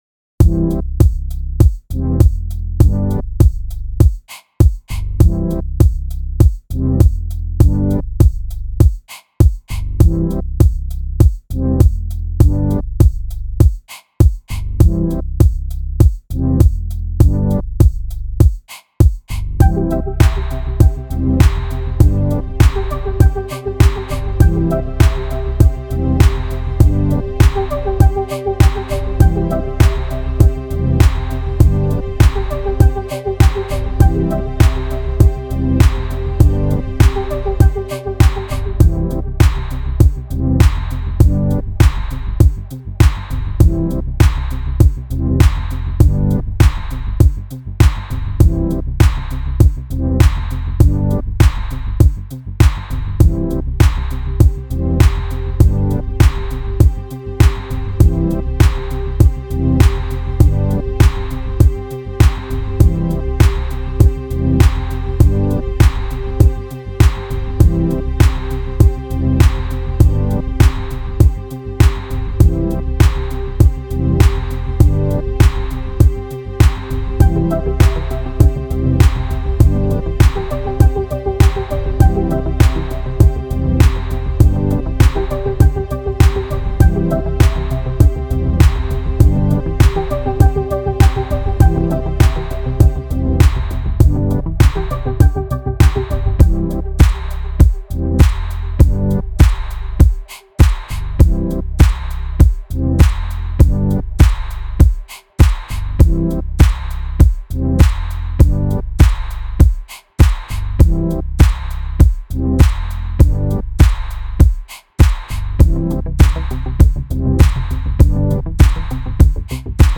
Télécharger Christine (instrumental).mp3